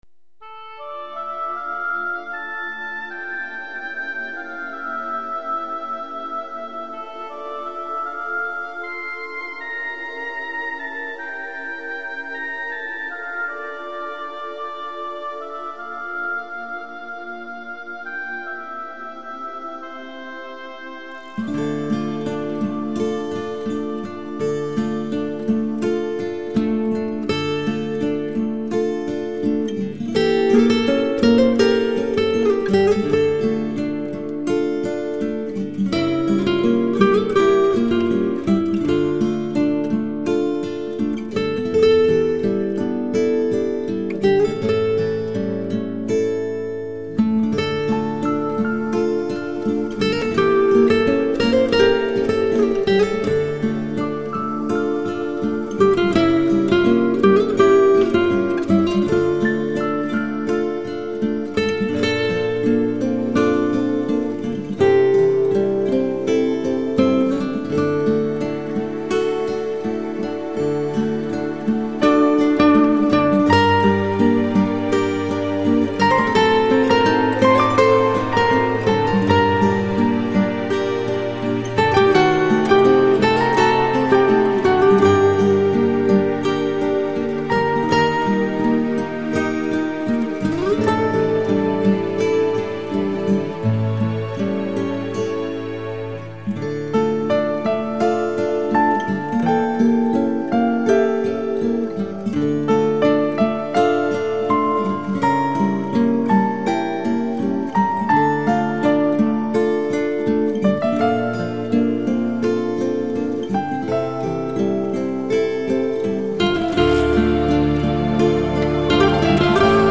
Звучание его гитары уникально!